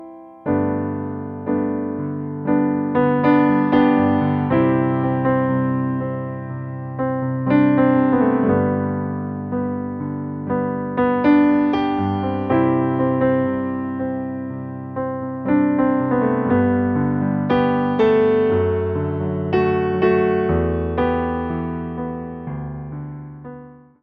Wersja demonstracyjna:
60 BPM
G – dur